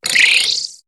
Cri de Tic dans Pokémon HOME .